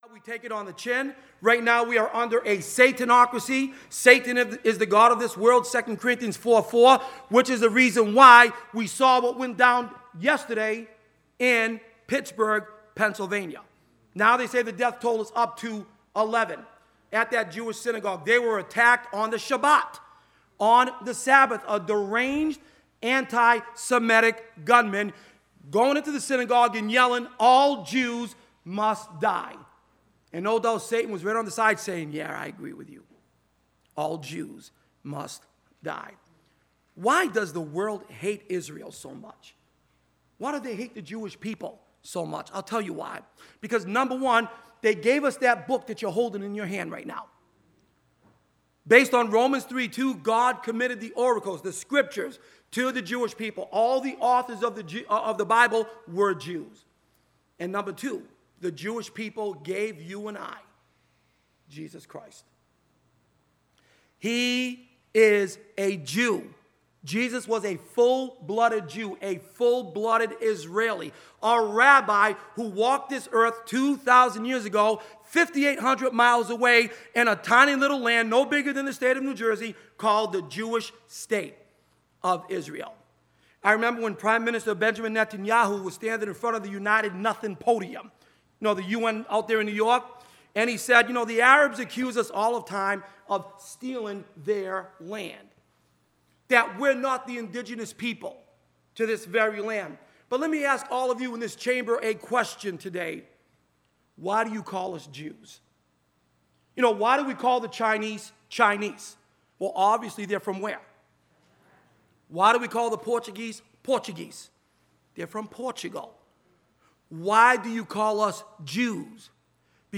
2018 Bible Prophecy Conference (Sunday Morning)